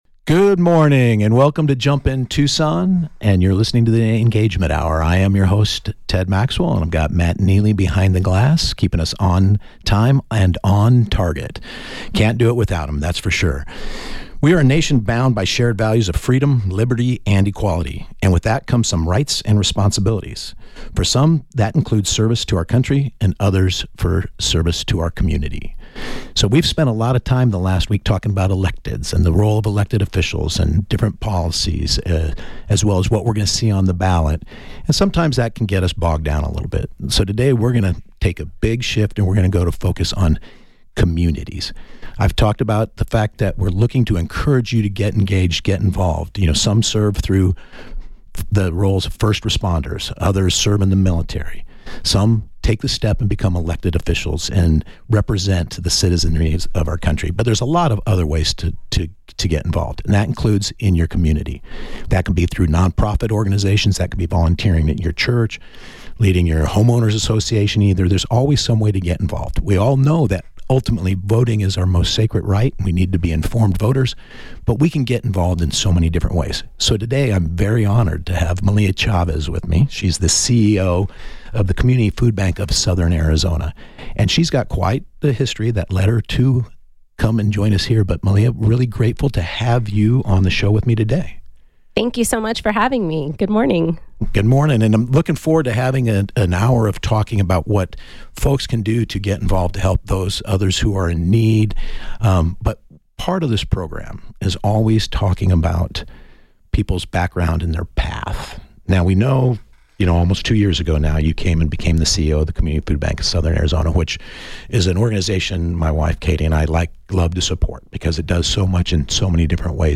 Based on the 8/13/24 Jump In Tucson on KVOI-1030AM in Tucson, AZ.